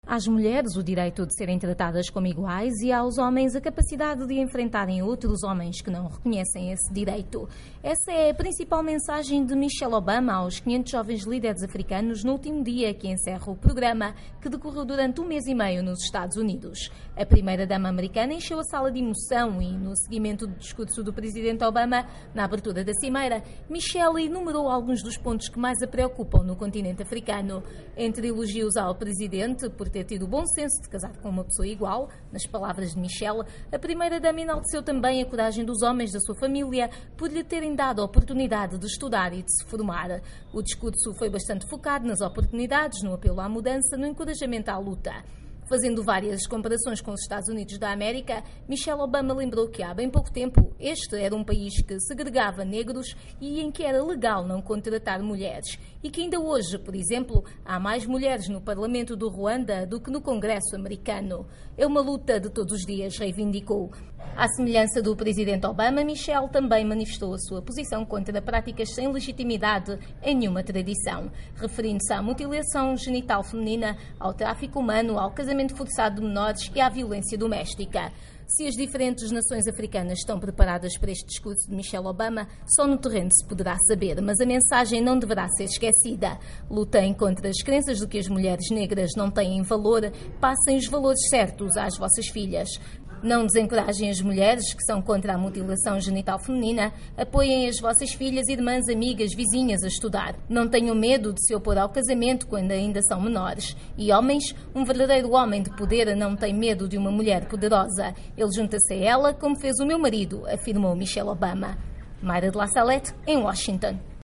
Embed share Michele Obama fala a líderes africanos jovens - 1:54 by Voz da América Embed share The code has been copied to your clipboard.